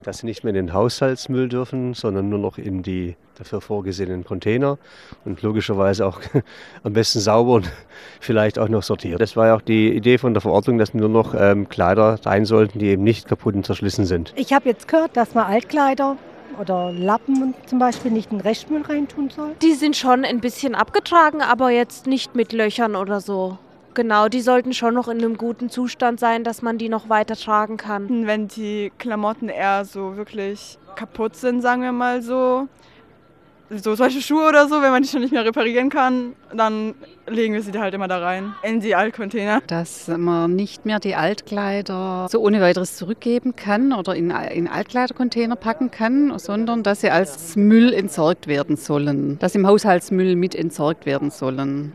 Bei einer Umfrage in der Heilbronner Innenstadt wissen viele Passantinnen und Passanten nicht genau, welche Textilien wo entsorgt werden sollen.